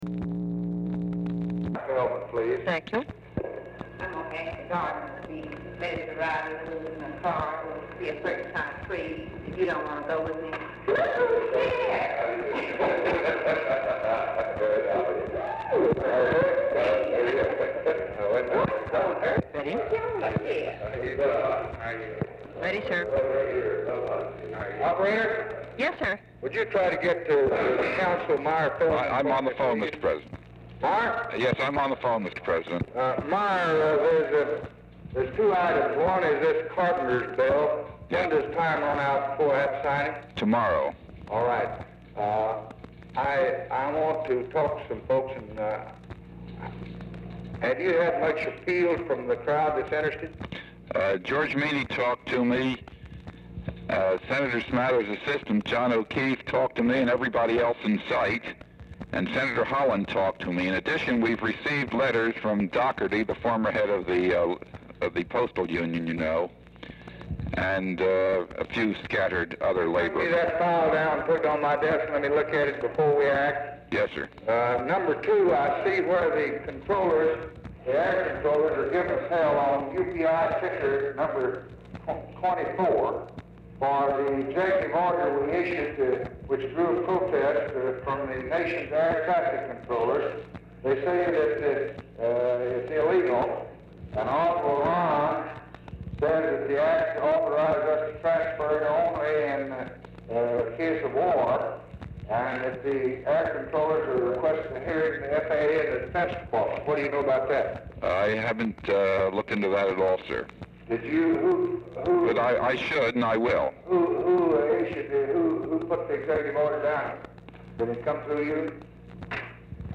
Telephone conversation # 4246, sound recording, LBJ and MYER FELDMAN, 7/16/1964, 11:15AM | Discover LBJ
OFFICE CONVERSATION PRECEDES CALL
LBJ ON SPEAKERPHONE
Format Dictation belt